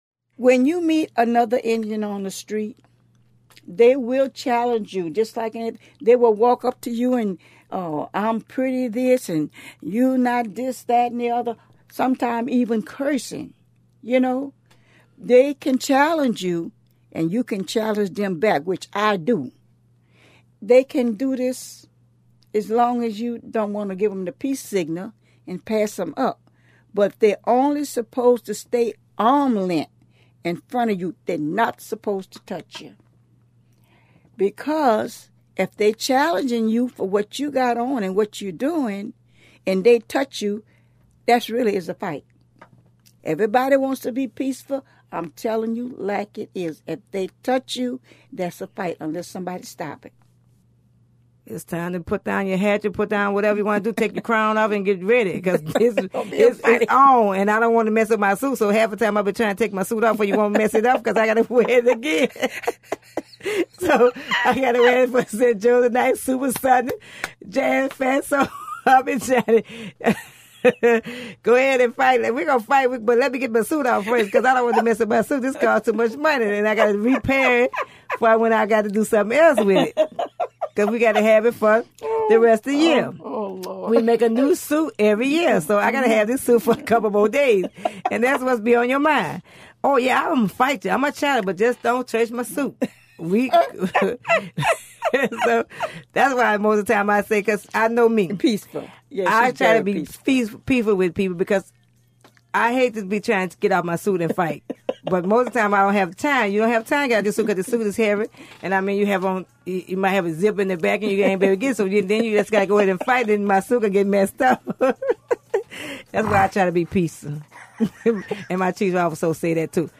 Conversation by conversation, interview by interview,StoryCorpscollects the stories and voices of our time.